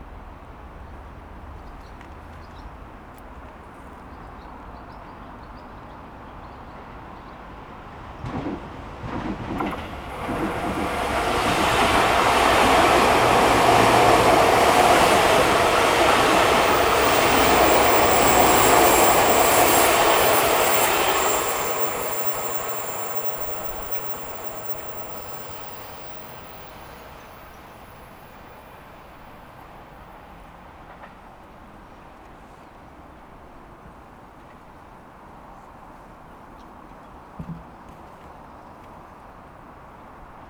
上り電車通過。
H2essential MS内蔵マイク指向性120°＋
ZOOM　ヘアリーウィンドスクリーン WSH-2e